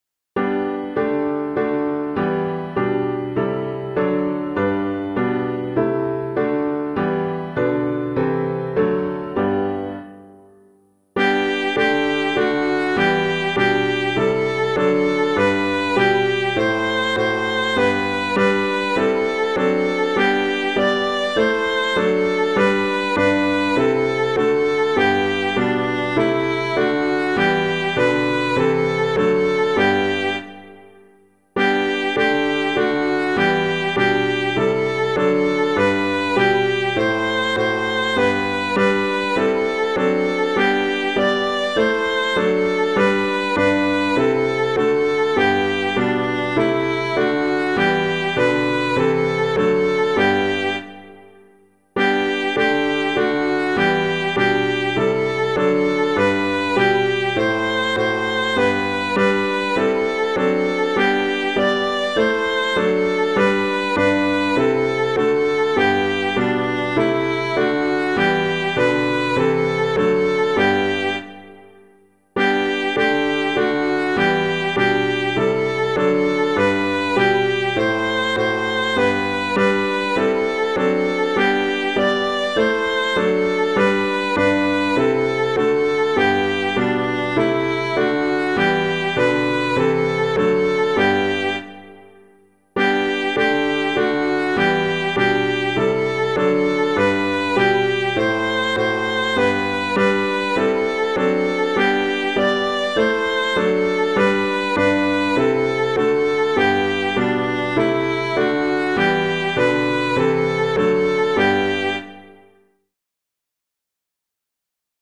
piano
Let All on Earth Their Voices Raise [Mant - TALLIS' CANON] - piano.mp3